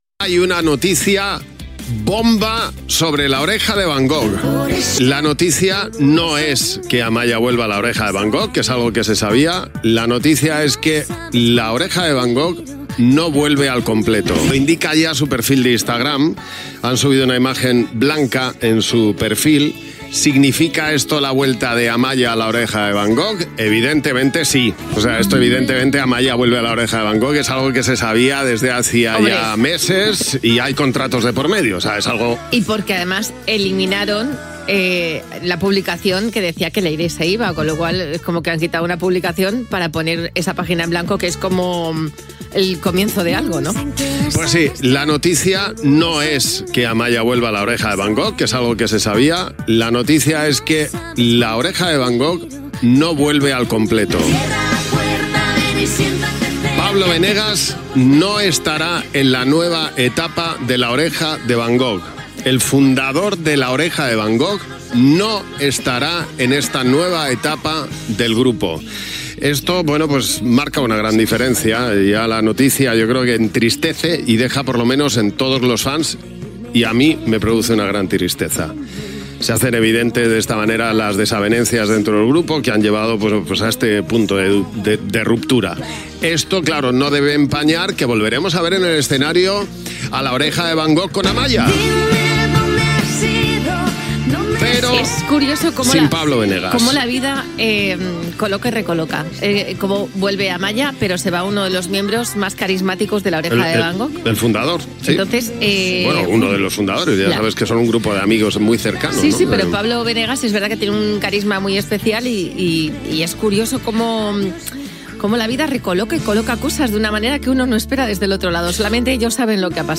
Musical
Presentador/a
Nieves, Javi
FM